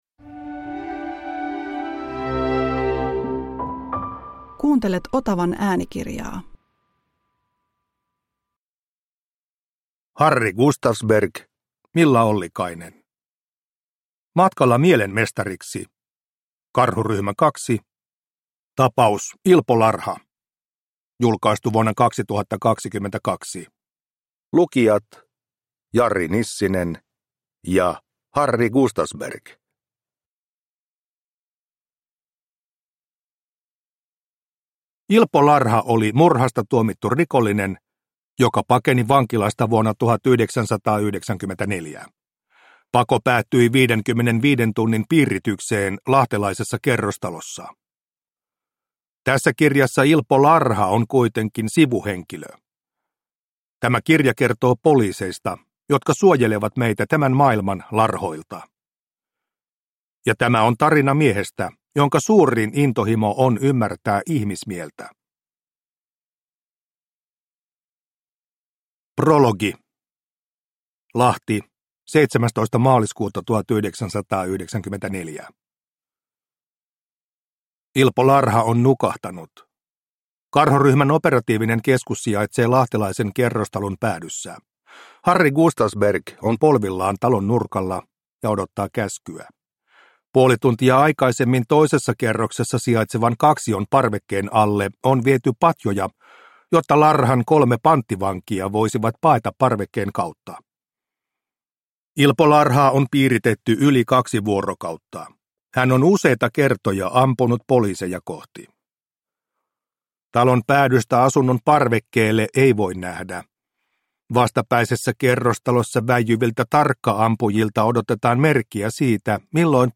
Karhuryhmä 2 – Ljudbok – Laddas ner